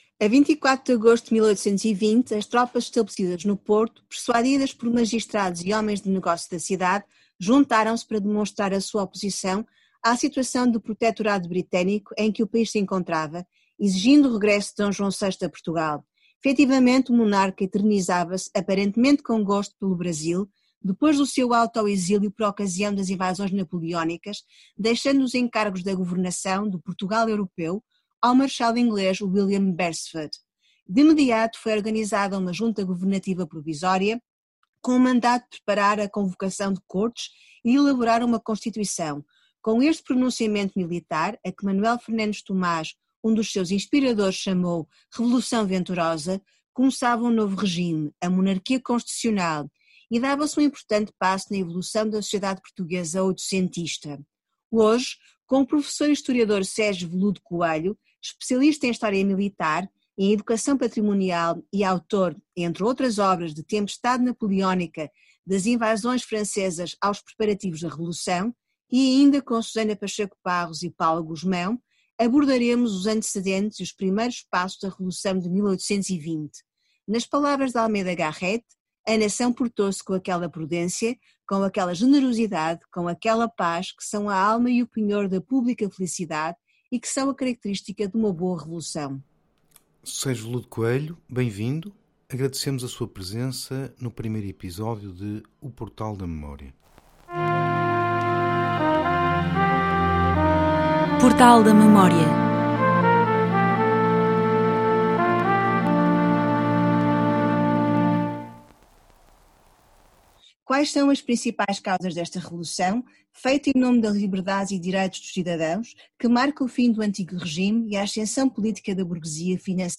Assim se inicia a revolução que conduziu à primeira Constituição portuguesa. Entrevista